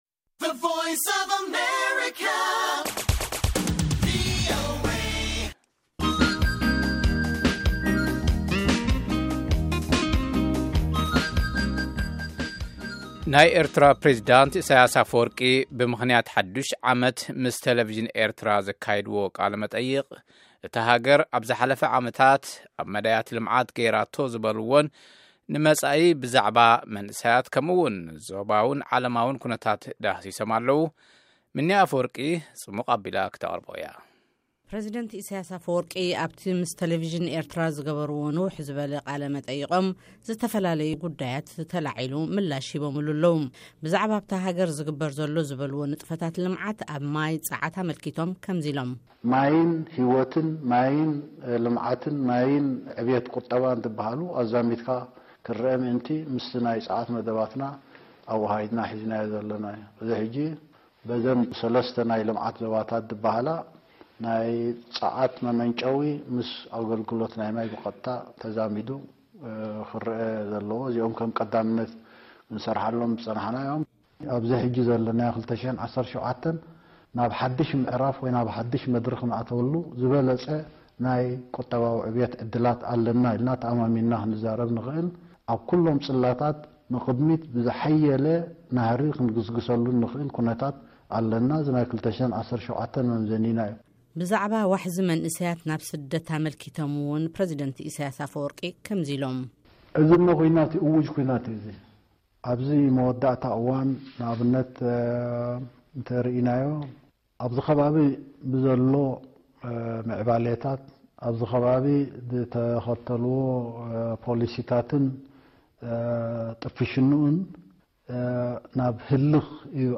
ጸብጻብ፡ ቃለ መጠይቕ ፕረዝደንት ኢሰያስ ኣፈወርቂ ምስ ቴሌቭዥን ኤርትራ